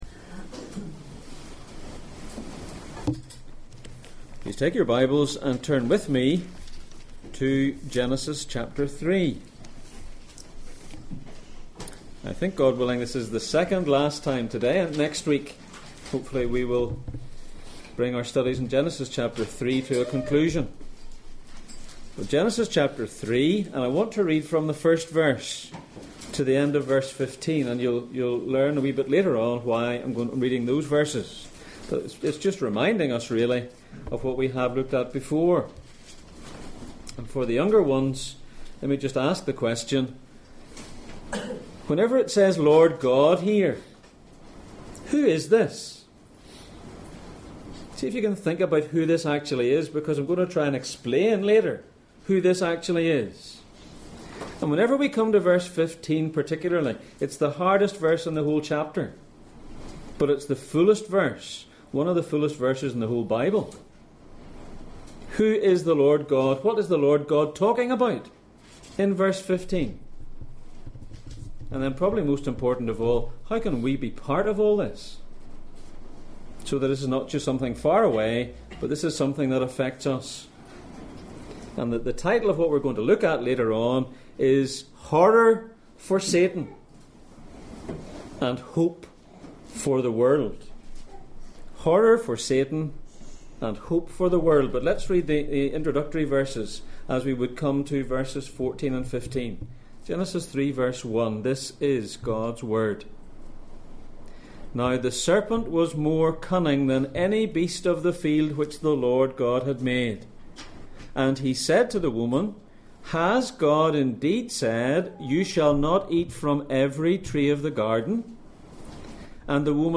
Passage: Genesis 3:1-15, Romans 16:17-20, 1 John 5:19 Service Type: Sunday Morning